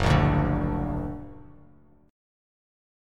F#+ Chord
Listen to F#+ strummed